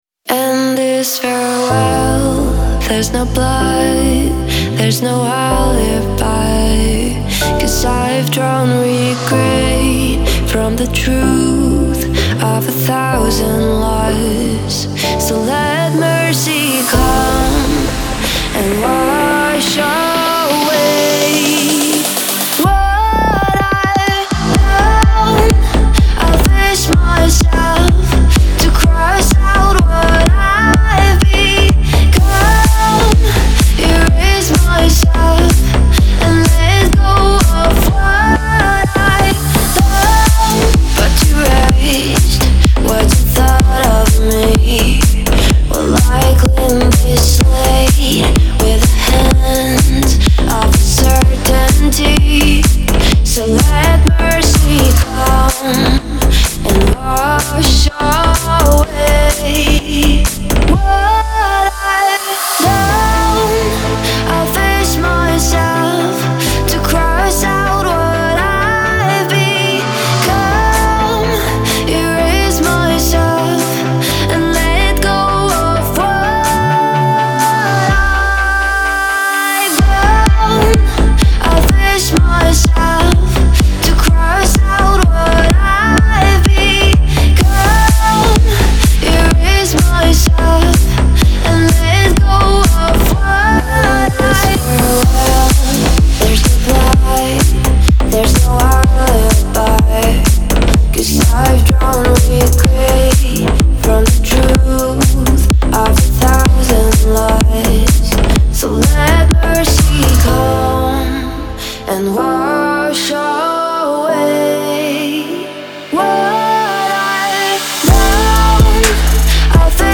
это трек в жанре электронной музыки с элементами поп и EDM